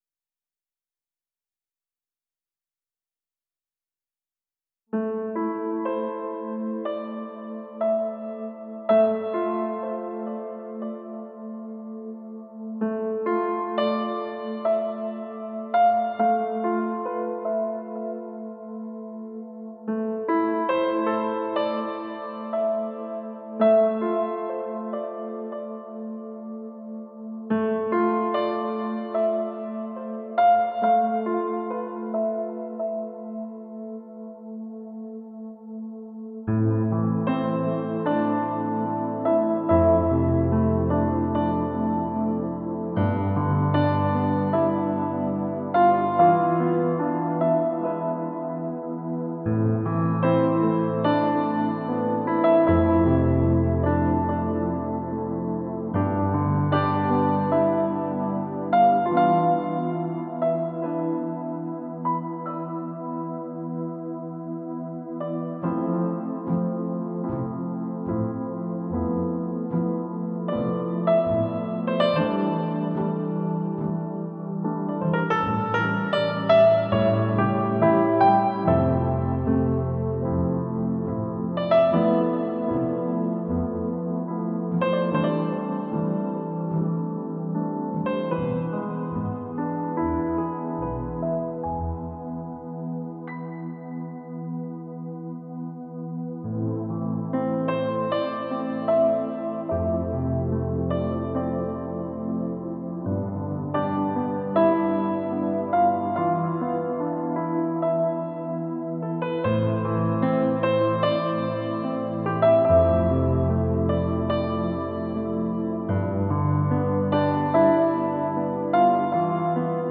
In fact if you want to know – the song is written in the key of C.